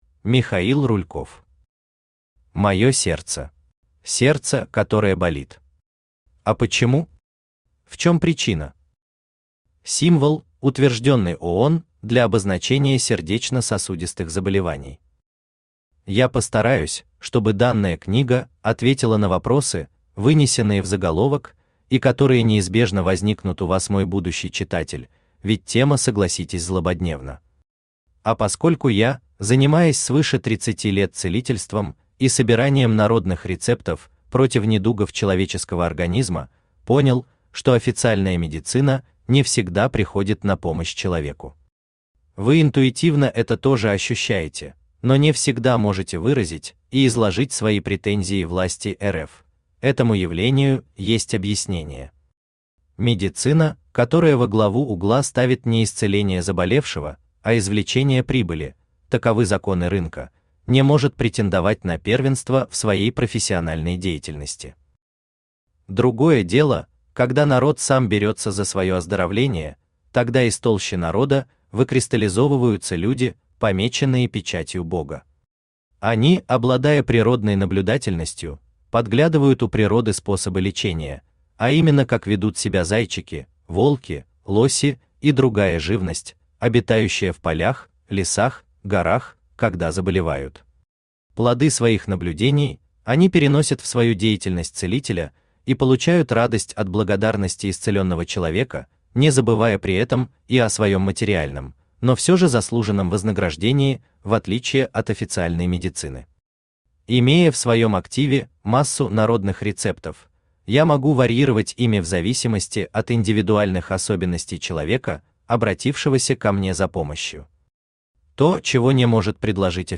Аудиокнига Мое сердце | Библиотека аудиокниг
Aудиокнига Мое сердце Автор Михаил Михайлович Рульков Читает аудиокнигу Авточтец ЛитРес.